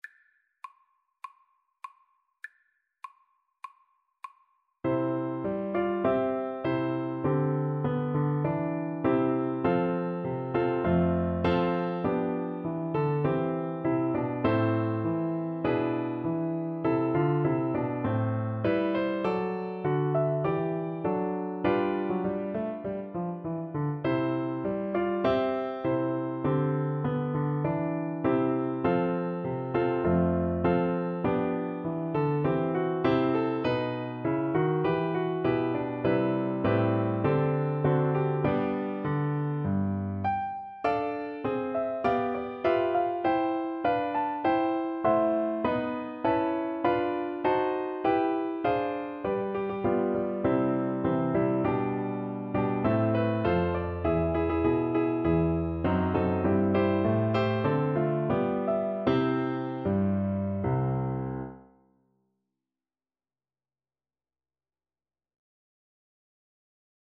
Play (or use space bar on your keyboard) Pause Music Playalong - Piano Accompaniment Playalong Band Accompaniment not yet available reset tempo print settings full screen
C major (Sounding Pitch) G major (French Horn in F) (View more C major Music for French Horn )
4/4 (View more 4/4 Music)
Traditional (View more Traditional French Horn Music)